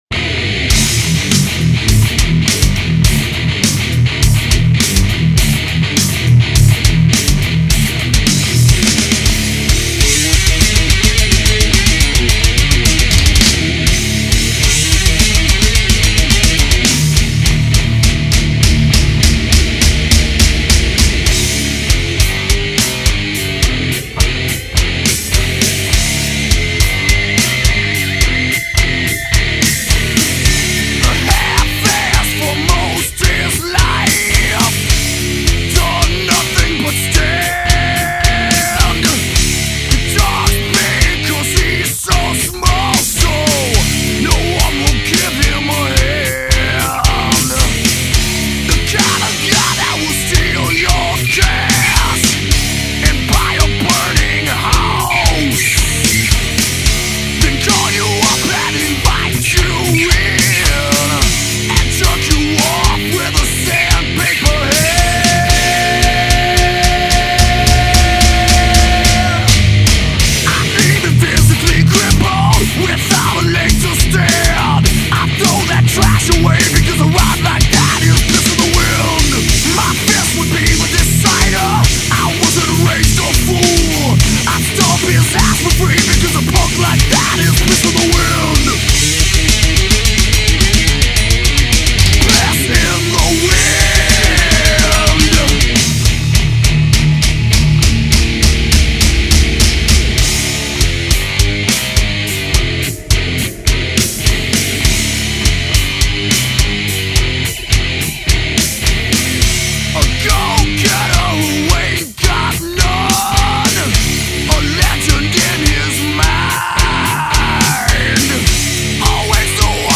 groove metal